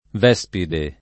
[ v $S pide ]